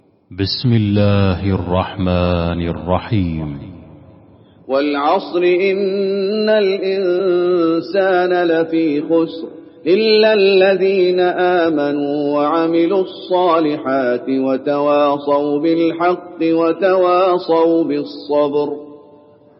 المكان: المسجد النبوي العصر